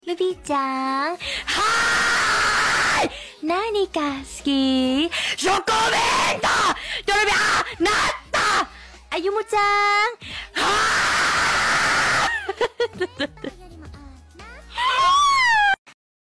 but someone is actually screaming